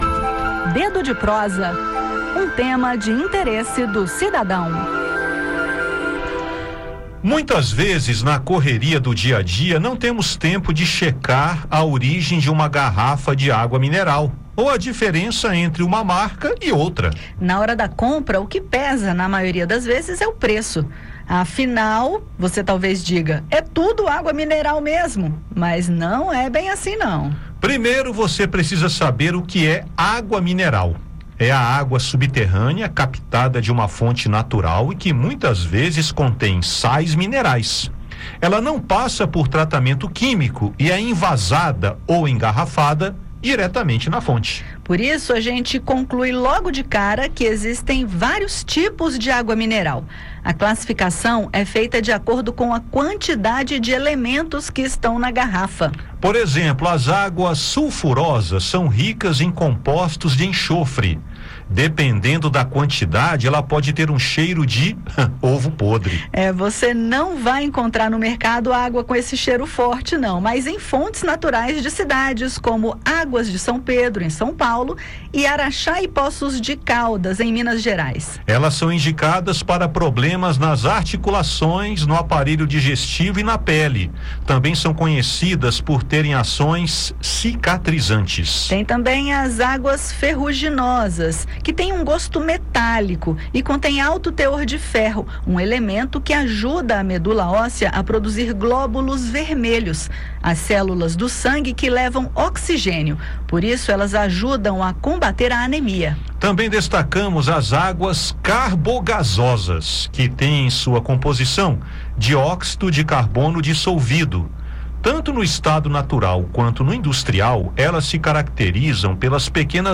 A classificação de cada tipo é feita de acordo com a quantidade de elementos e minerais que compõem a água. No bate-papo desta edição, destacamos os tipos, características e as informações que podem ser encontradas nos rótulos.